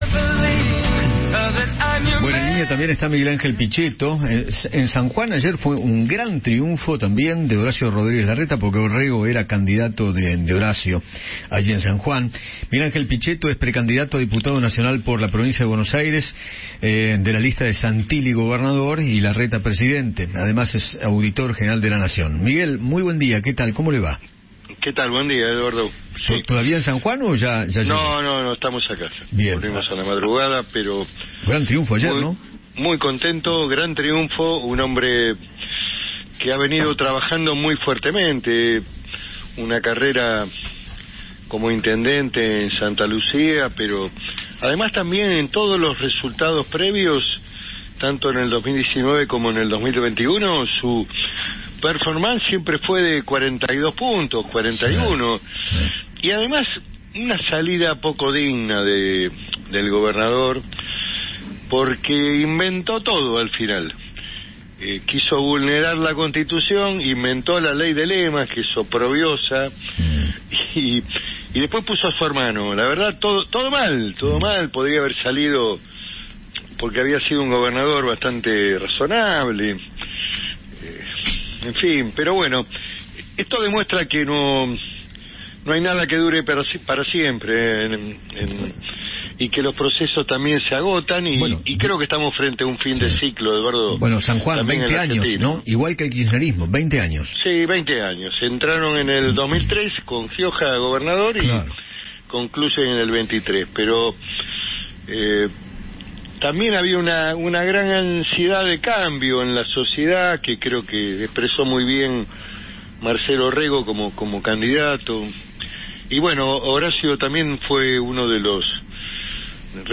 Miguel Ángel Pichetto, Auditor General de la Nación y pre candidato a diputado, dialogó con Eduardo Feinmann sobre el triunfo electoral en San Juan y el panorama para las elecciones nacionales.